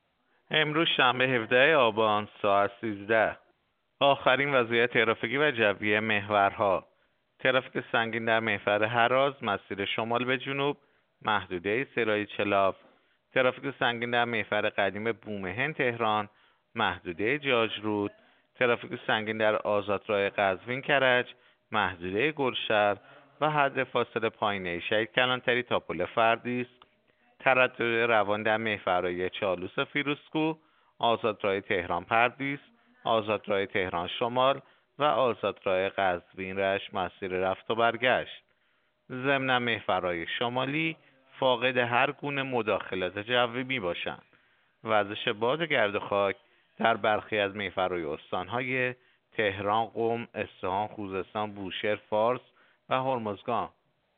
گزارش رادیو اینترنتی از آخرین وضعیت ترافیکی جاده‌ها ساعت ۱۳ هفدهم آبان؛